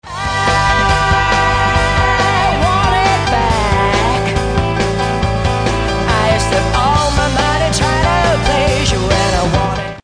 Clavinet Hater Version